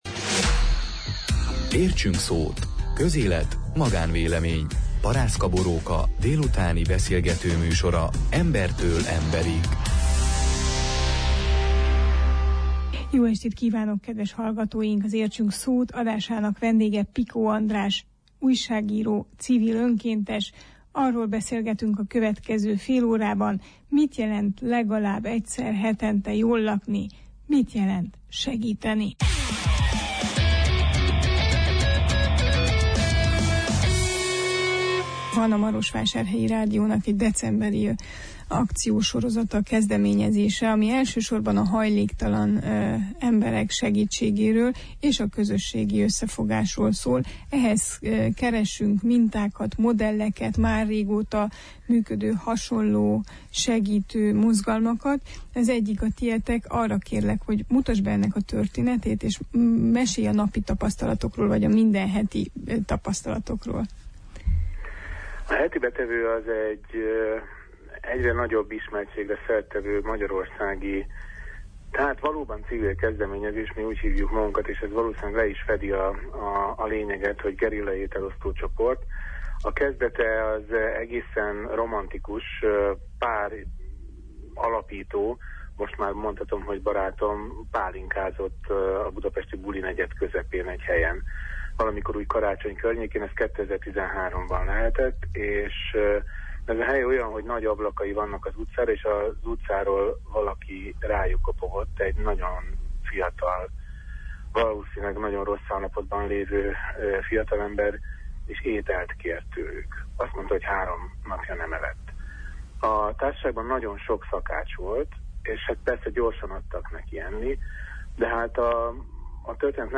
Interjúnkat abban a reményben rögzítettük, hogy a kezdeményezés Székelyföldön is elterjed.